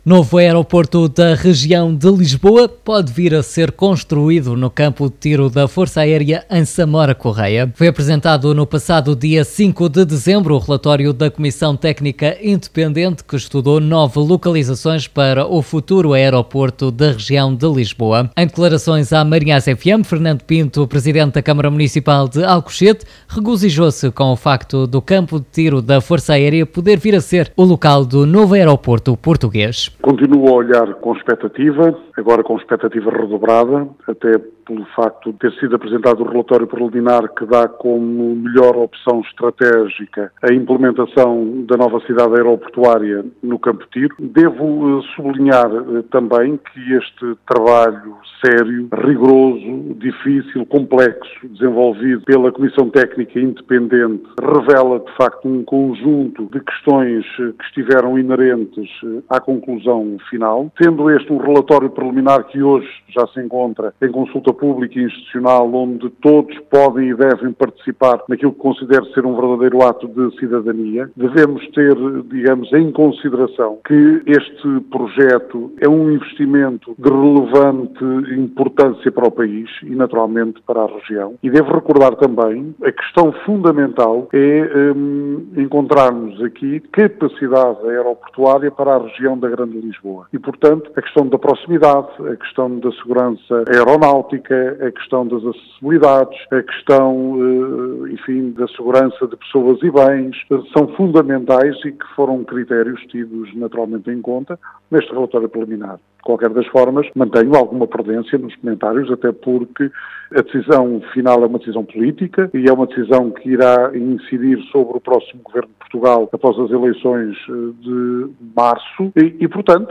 RM Entrevista: Presidente da Câmara de Alcochete refere que, após as eleições, “se exige uma decisão política” (c/ som)
Escute, aqui, as declarações do Presidente da Câmara de Alcochete à Antena da MarinhaisFM: